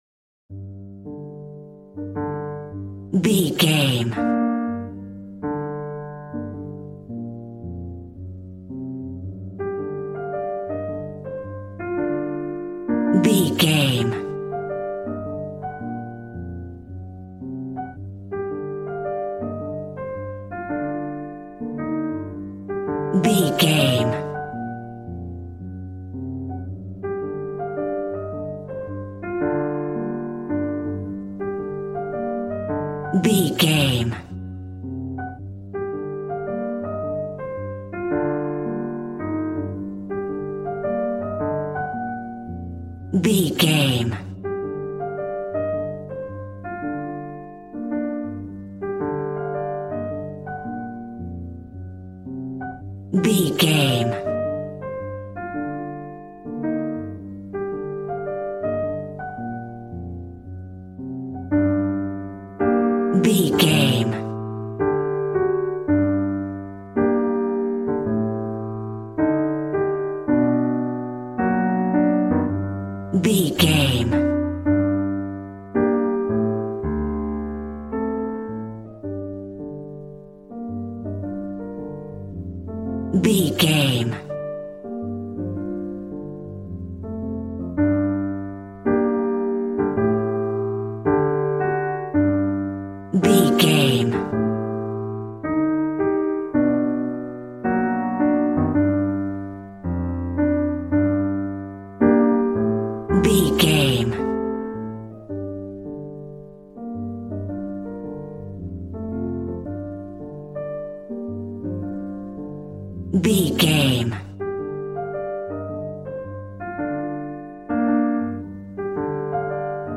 Aeolian/Minor
smooth
drums